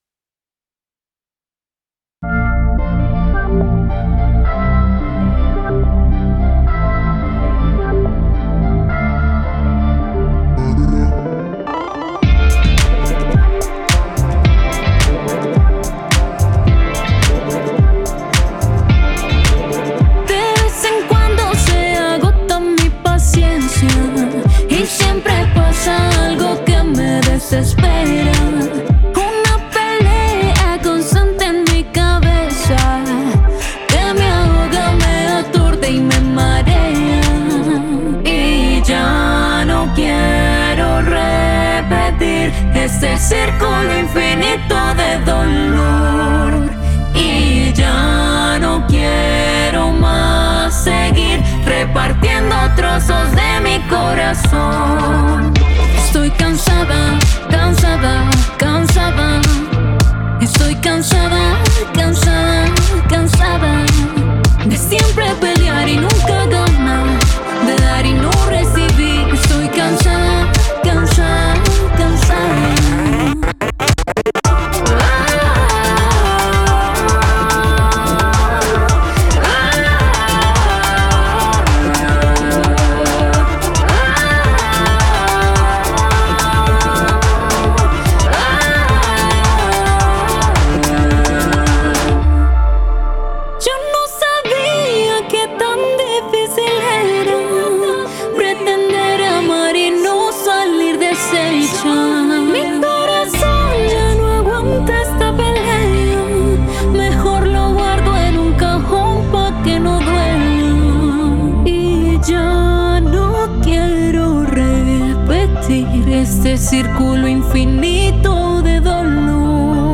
El género central que explora este álbum es el reggaetón fusionado con pop, R&B y dream pop.